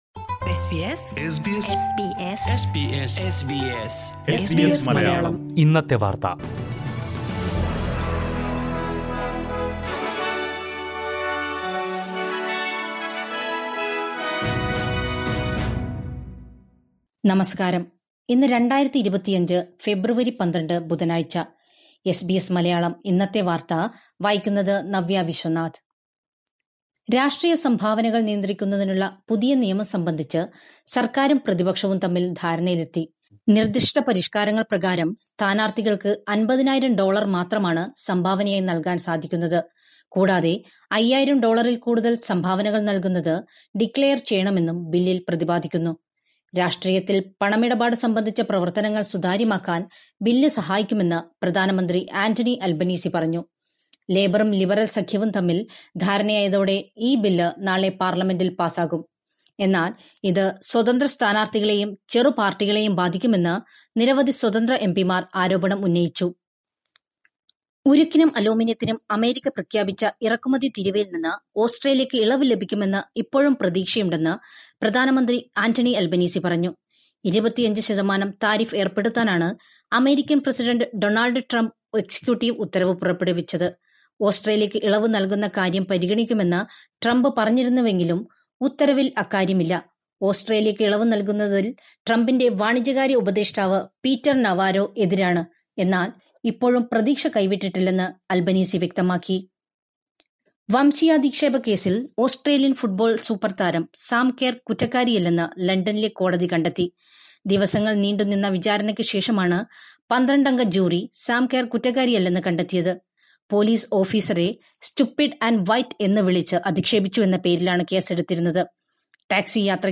2025 ഫെബ്രുവരി 12ലെ ഓസ്‌ട്രേലിയയിലെ ഏറ്റവും പ്രധാന വാര്‍ത്തകള്‍ കേള്‍ക്കാം...